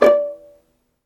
VIOLINP EN-L.wav